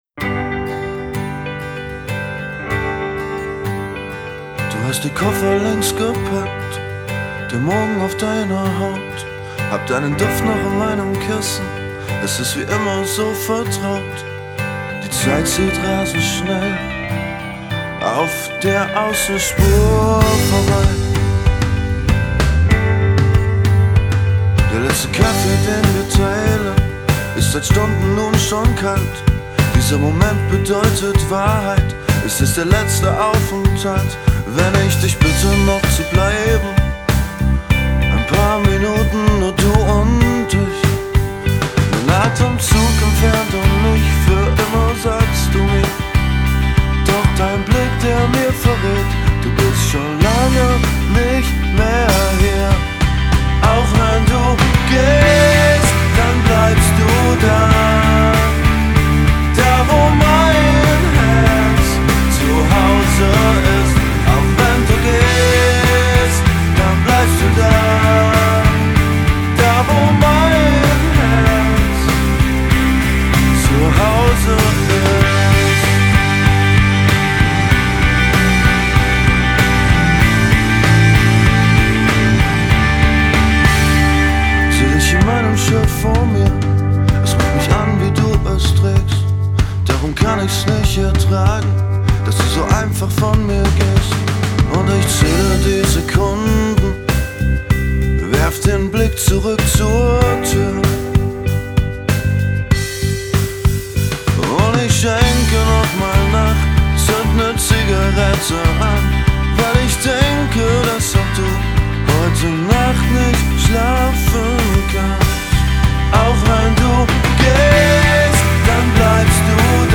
Gesang
Keys
Drums
Bass